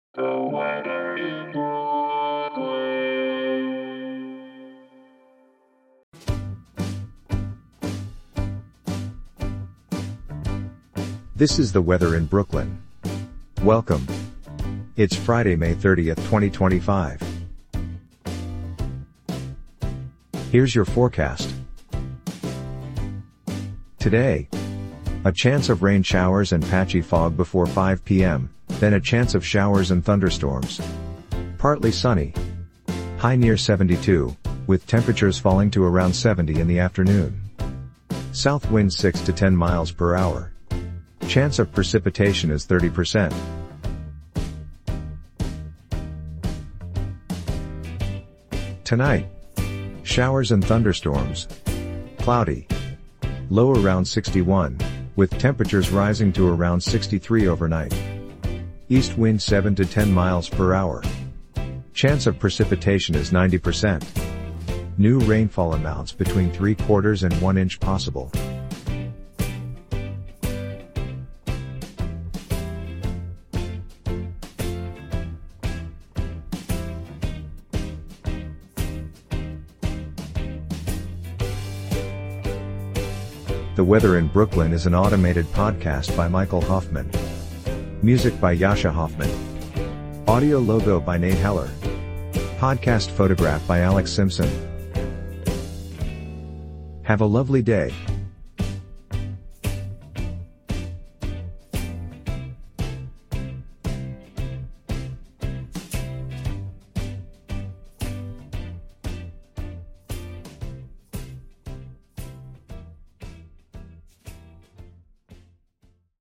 is generated automatically